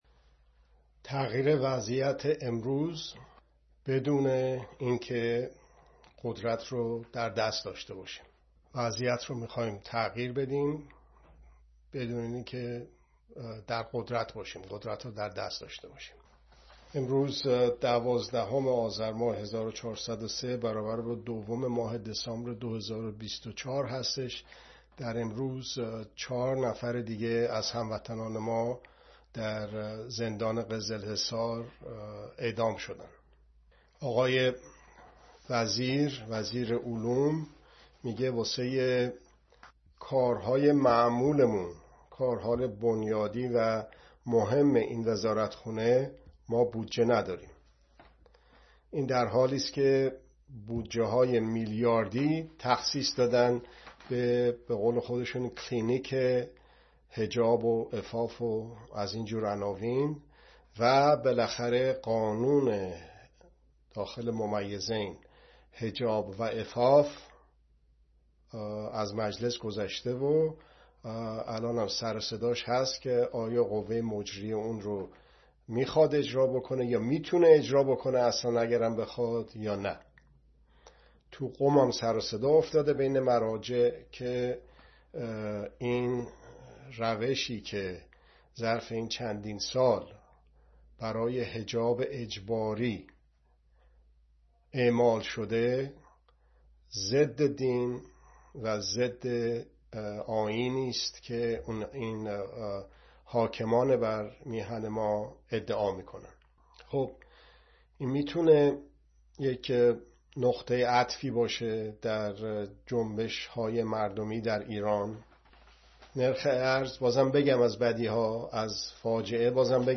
گزارش، بررسی، و ارزیابی رویدادها ❊ (دوشنبه‌ها ساعت۲۱ به‌وقت ایران به‌صورت زنده)